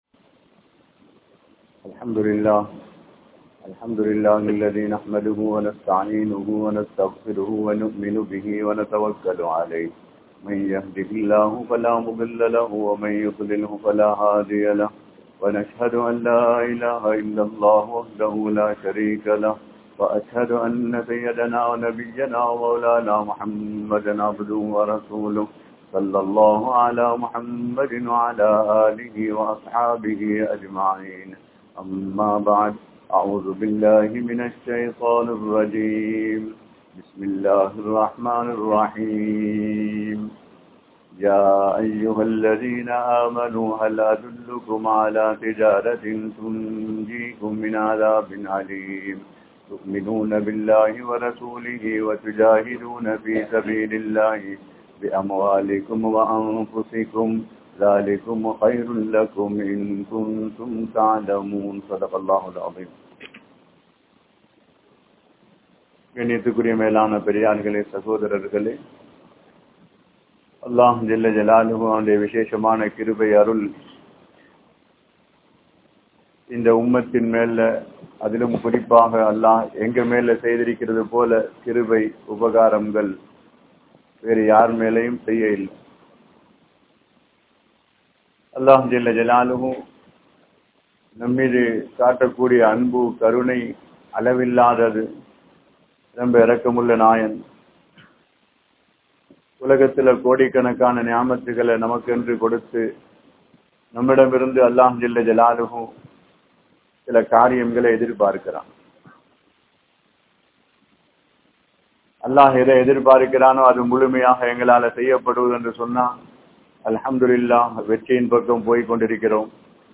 Intha Ummaththin Poruppu (இந்த உம்மத்தின் பொறுப்பு) | Audio Bayans | All Ceylon Muslim Youth Community | Addalaichenai